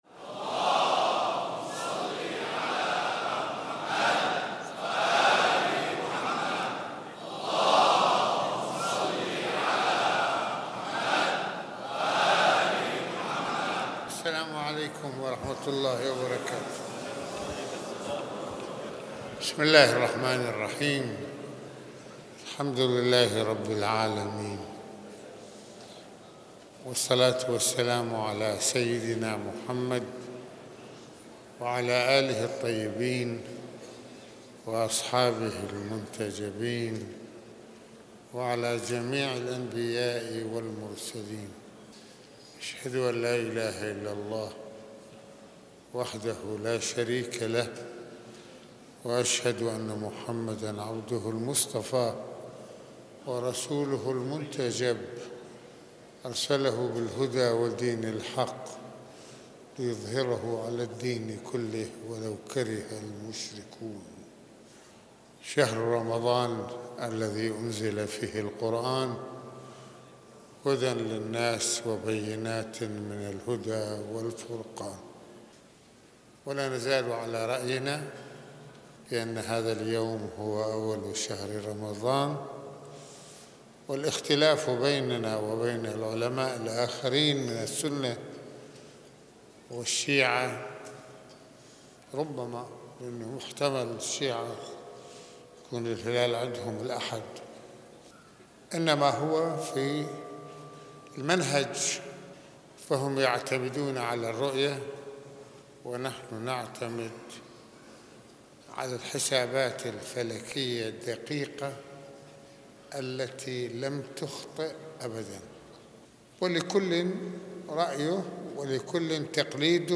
- المناسبة : خطبة الجمعة المكان : مسجد الإمامين الحسنين (ع) المدة : 24د | 51ث المواضيع : شهر رمضان: شهر الثّقافة والتربية الروحيّة - الاختلاف في بداية الشّهر - شهر البركة والمغفرة - ضيافة الله - فضل الصّيام في شهر رمضان - واجبات الصّائم الأخلاقيّة - أفضل الأعمال في هذا الشهر.